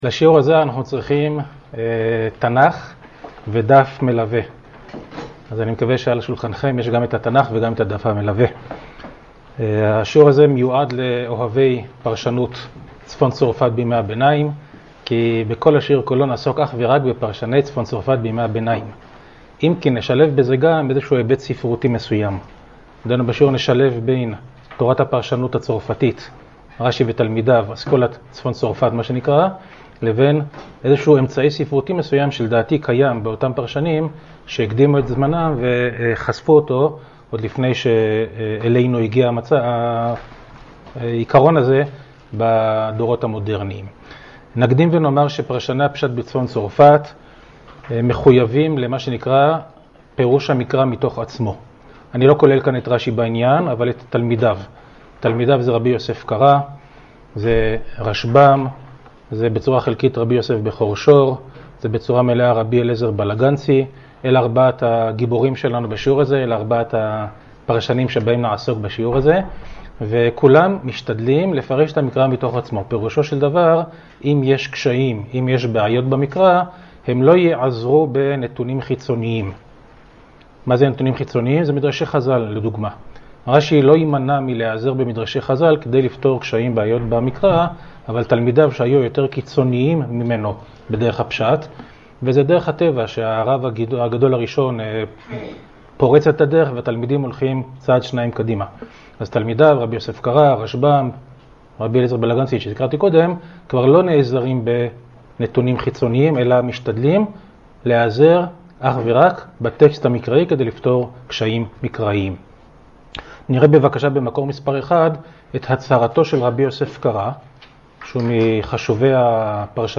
השיעור באדיבות אתר התנ"ך וניתן במסגרת ימי העיון בתנ"ך של המכללה האקדמית הרצוג תשפ"א